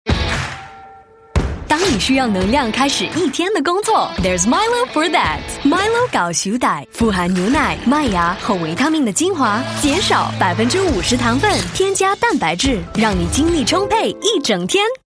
Voice Samples
female